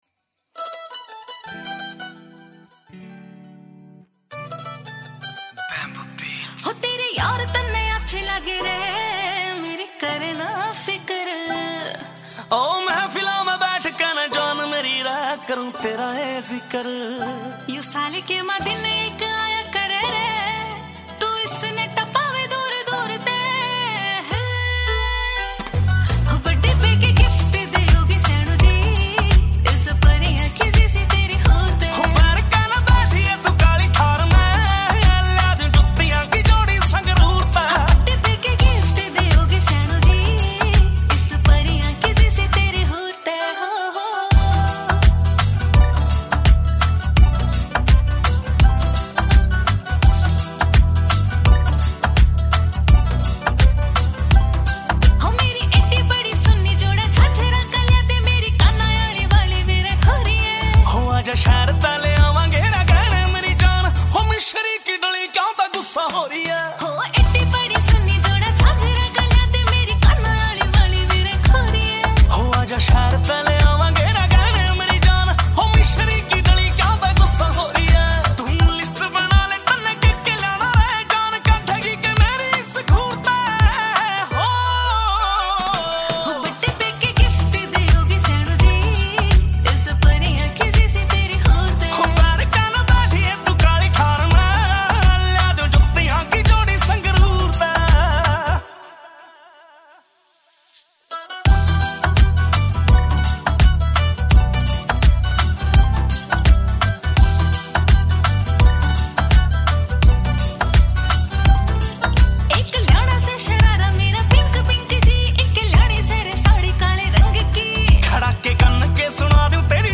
Category: Haryanvi Songs